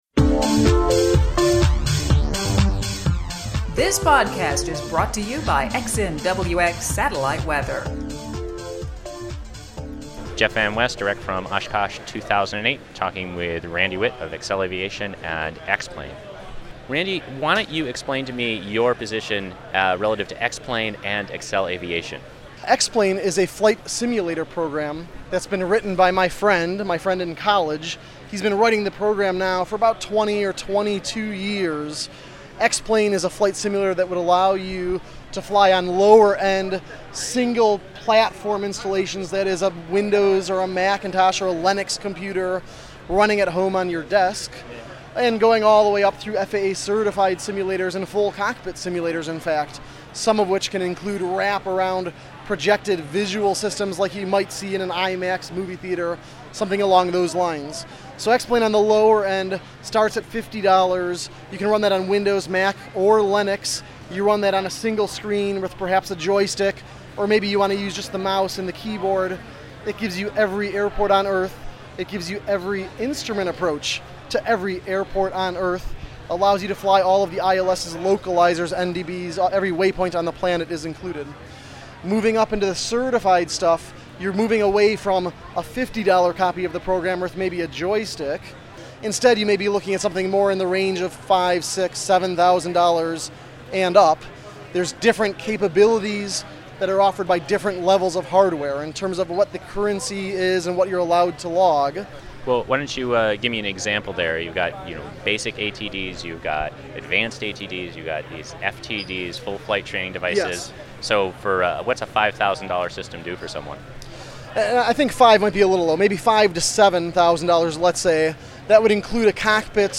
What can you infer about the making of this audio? this week's podcasts all feature interviews and audio direct from EAA AirVenture 2008 in Oshkosh, Wisconsin (WI).